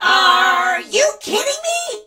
darryl_die_vo_06.ogg